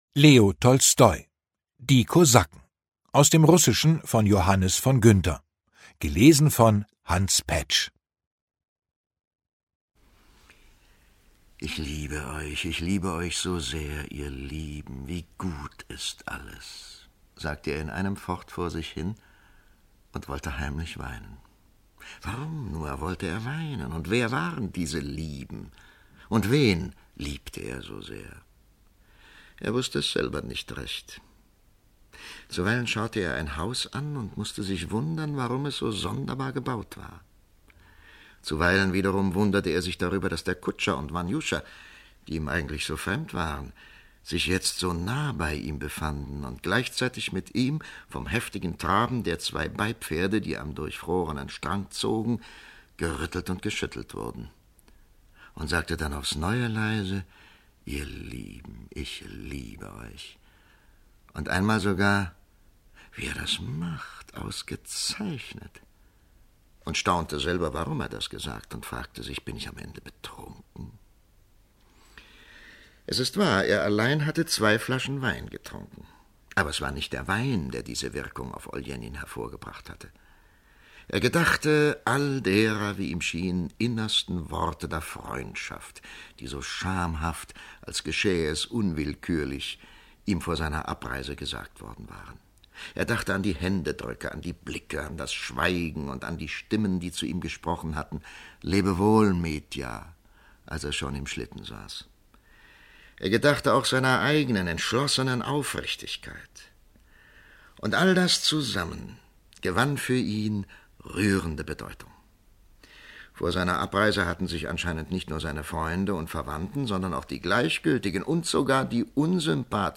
Hans Paetsch (Sprecher)
Hingebungsvoll und unverwechselbar liest Hans Paetsch diese Geschichte voller Sehnsüchte und unlösbarer Konflikte.